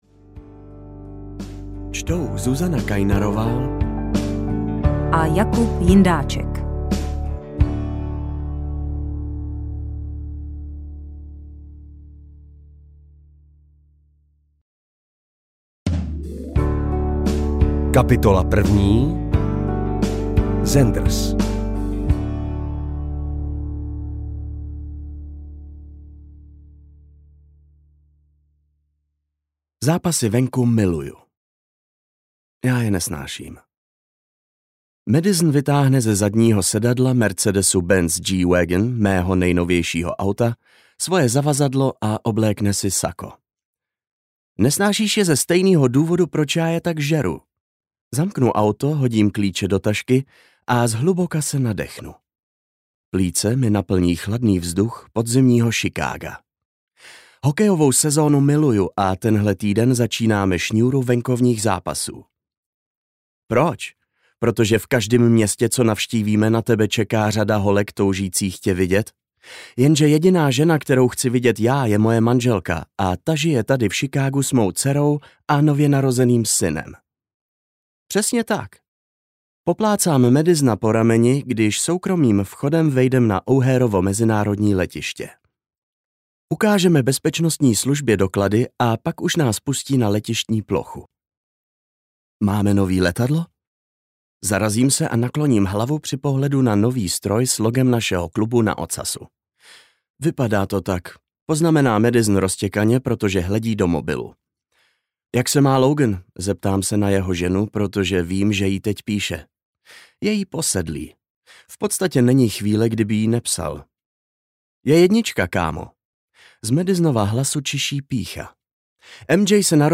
Mílový klub audiokniha
Ukázka z knihy
milovy-klub-audiokniha